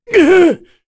Dakaris-Vox_Damage_kr_04.wav